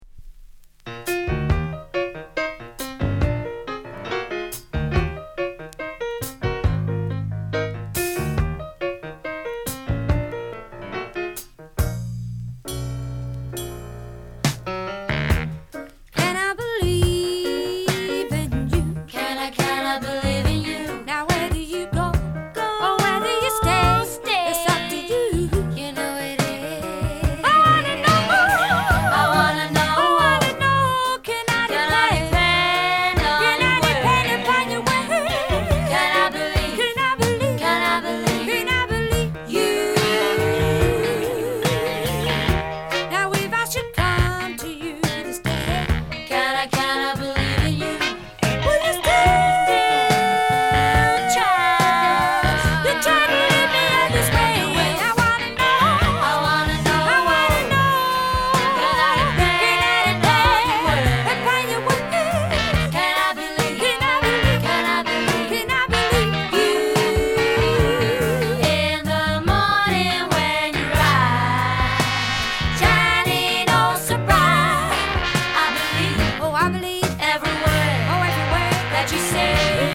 Genre:  Soul /Funk